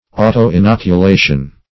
Search Result for " auto-inoculation" : The Collaborative International Dictionary of English v.0.48: Auto-inoculation \Au`to-in*oc`u*la"tion\, n. [Auto- + inoculation.]
auto-inoculation.mp3